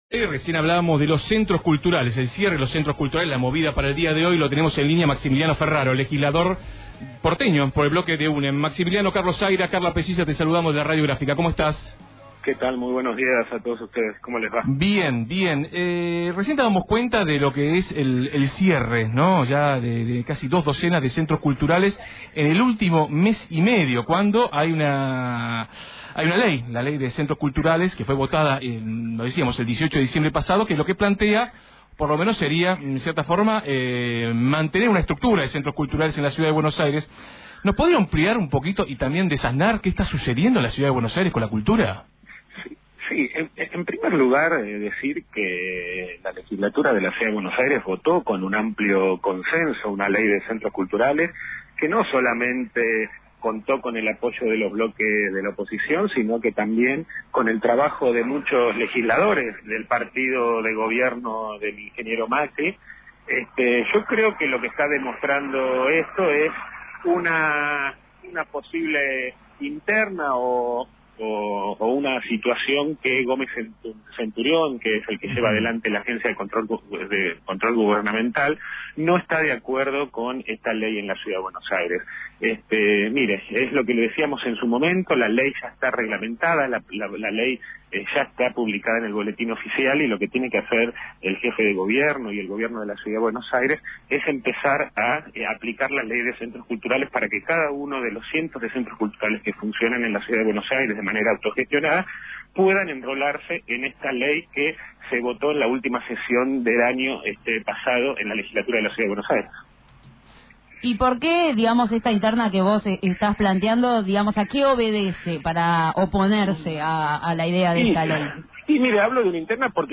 En comunicación con Radio Gráfica, el Legislador Maximiliano Ferraro (UNEN) le apuntó a Juan José Gómez Centurión, titular de la Agencia de Control: «La legislatura votó con amplio consenso una Ley de Centros Culturales con votos del bloque opositor y el trabajo de legisladores del partido del Ingeniero Macri.